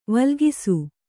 ♪ valgisu